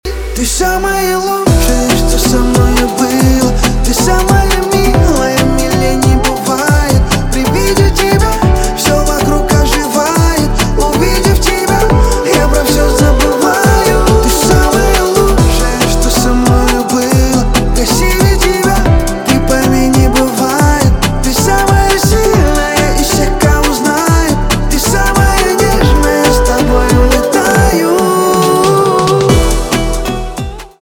поп
битовые , пианино
романтические